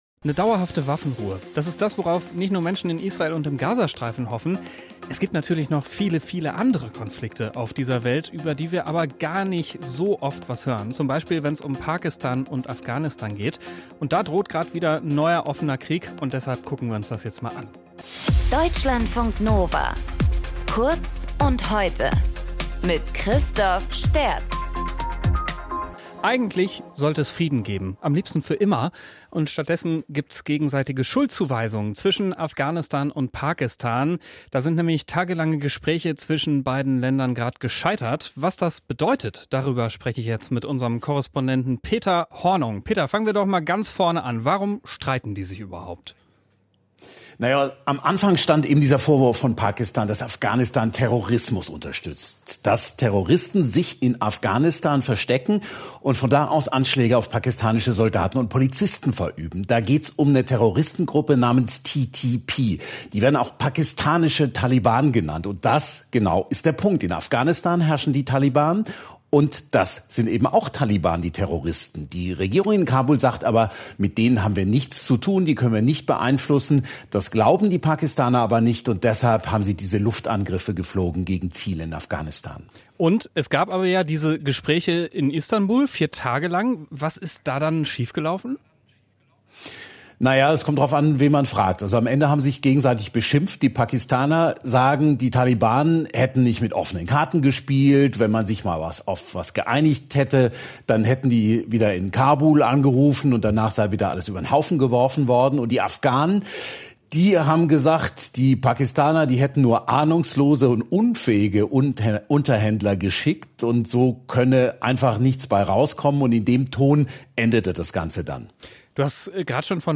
Moderator
Gesprächspartner